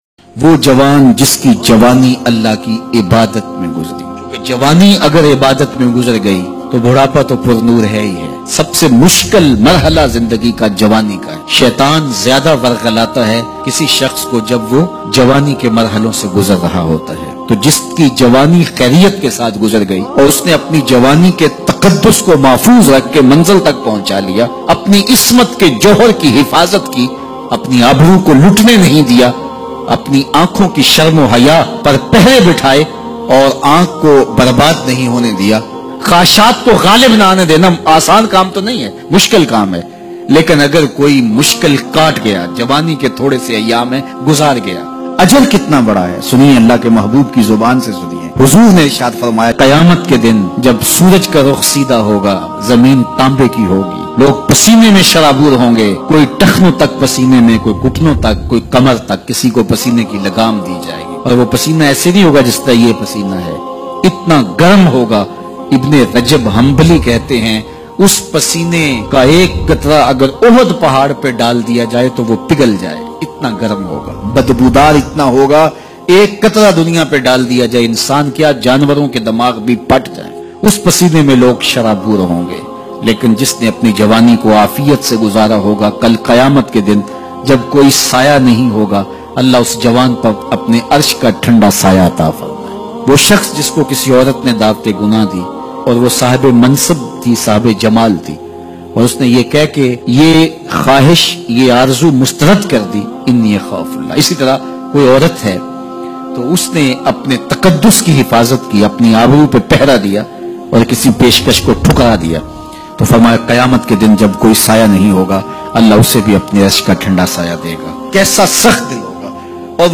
Jawani ki hifazat bayan mp3